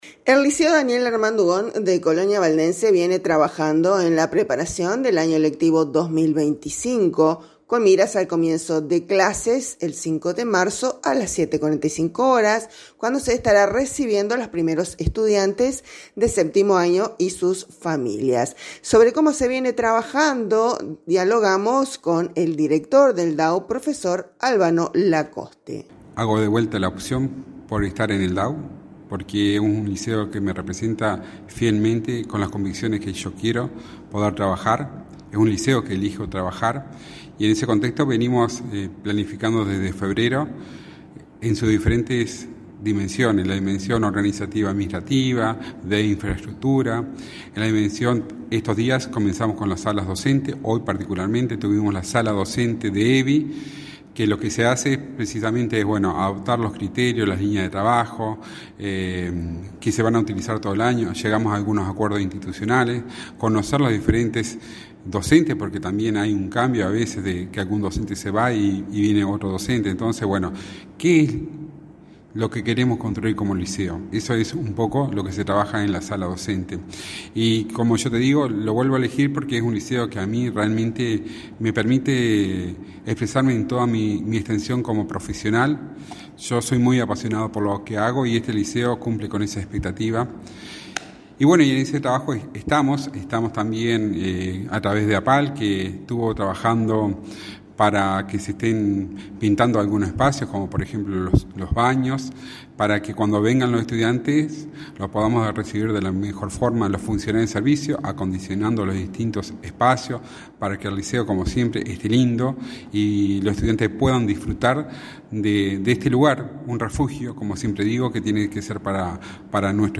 Sobre todo esto dialogamos